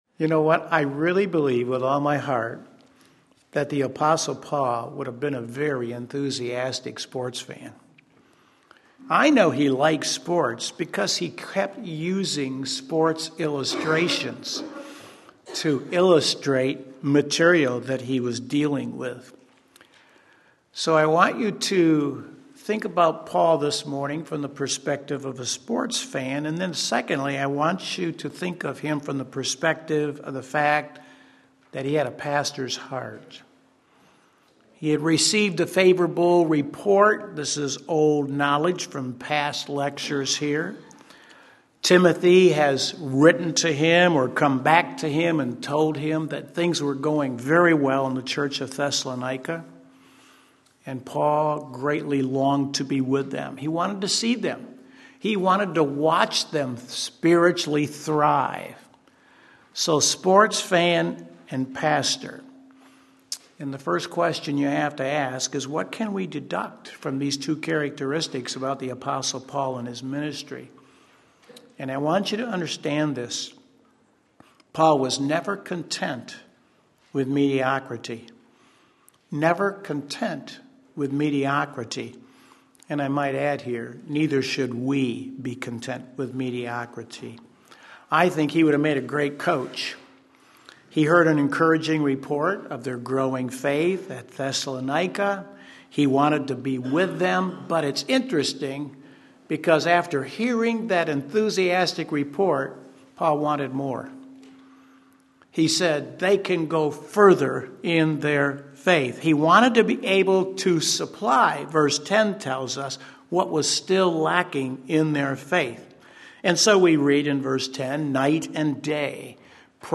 Sermon Link